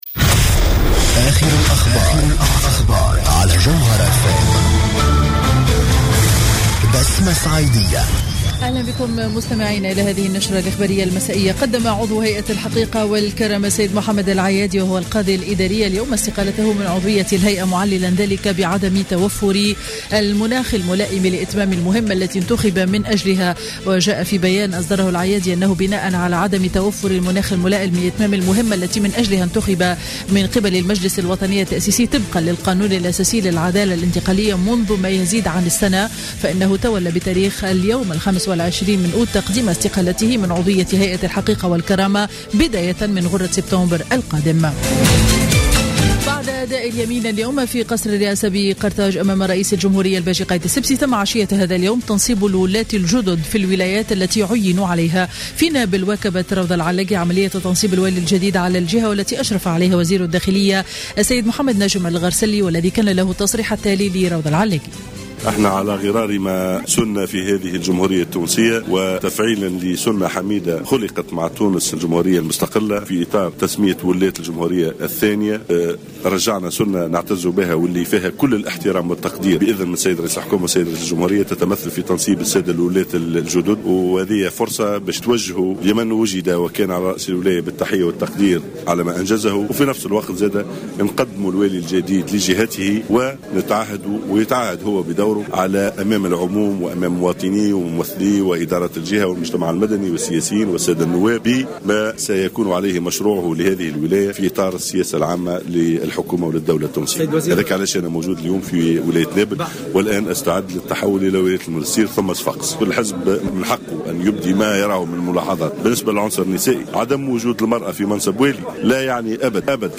نشرة أخبار السابعة مساء ليوم الثلاثاء 25 أوت 2015